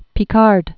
(pē-kärd, -kär), Auguste 1884-1962.